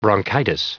Prononciation du mot bronchitis en anglais (fichier audio)
bronchitis.wav